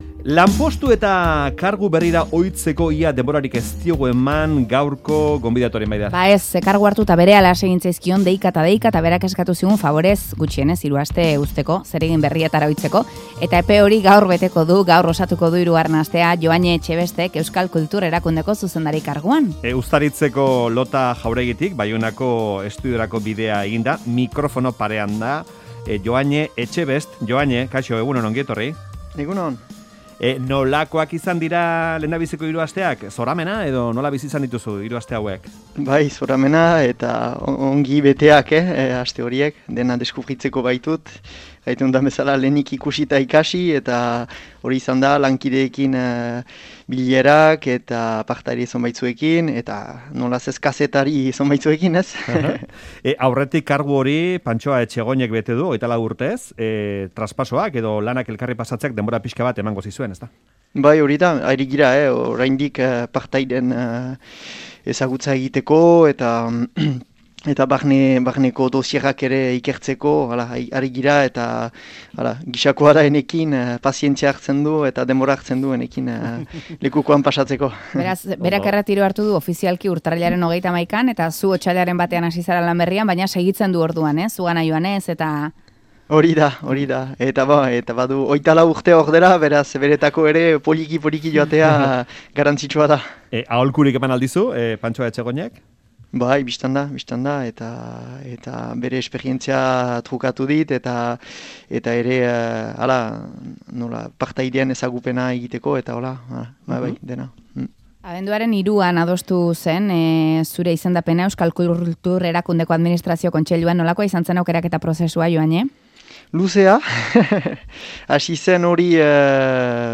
Uztaritzeko Lota jauregitik Baionako estudioetara egin du, gaur Faktorian zuzenean aritzeko.